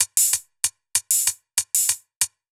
Index of /musicradar/ultimate-hihat-samples/95bpm
UHH_ElectroHatD_95-02.wav